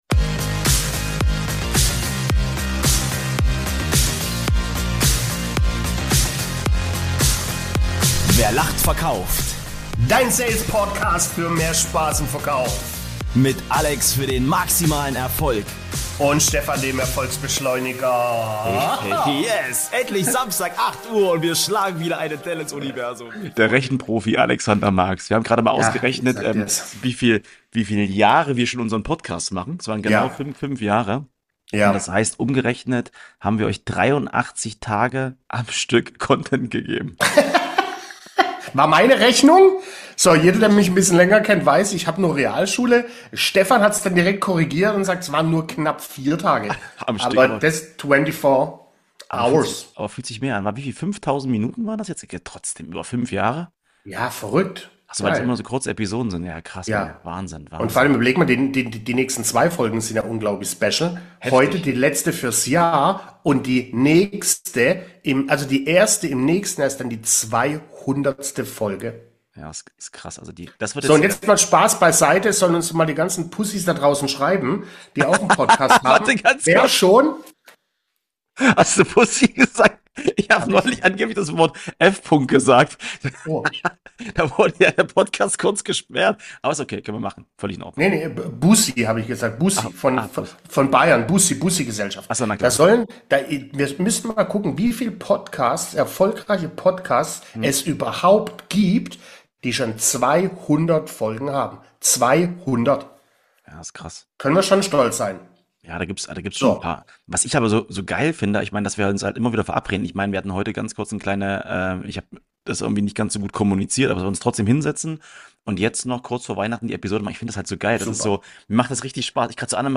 Es geht um das Jahr der Transformation, um Mut statt Kopfkino, um innere Ruhe trotz Tempo – und um die Erkenntnis, dass echtes Wachstum selten laut beginnt. Eine Folge voller Humor, Tiefe und ehrlicher Rückblicke. Kein Jahresrückblick von der Stange, sondern ein Gespräch mit Herz, Haltung und klarer Kante.